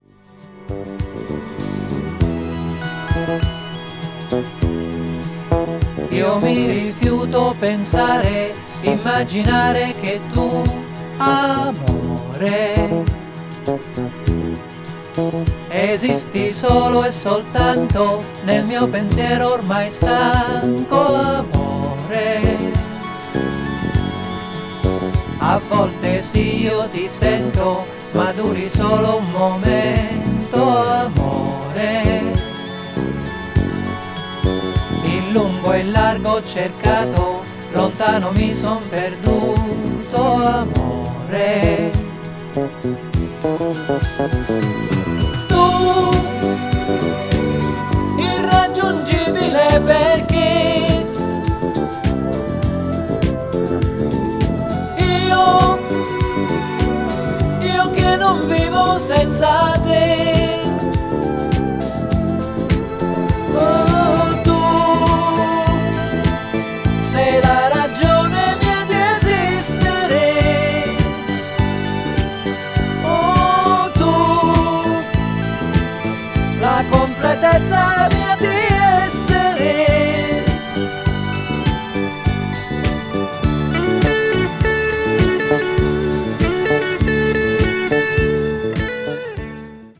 a.o.r.   italian pop   mellow groove   progressive rock